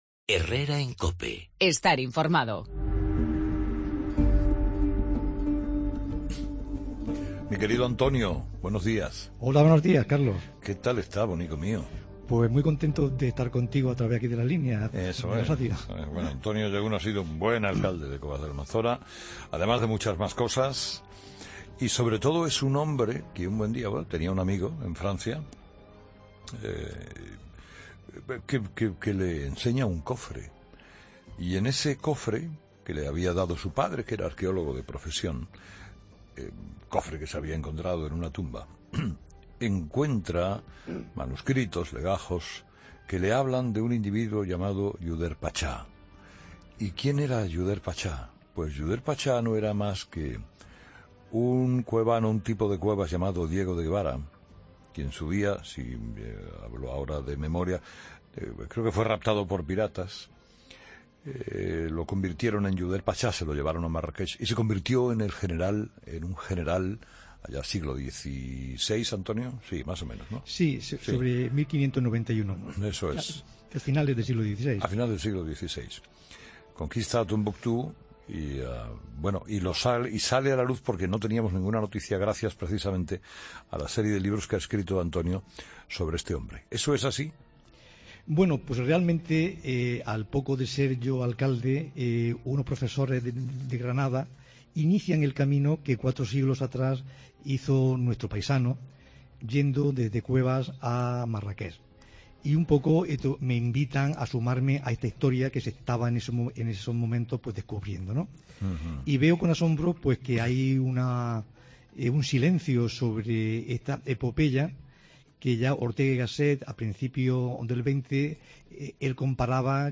Escucha la entrevista a Antonio LLaguno autor de "El eunuco de Tombuctú"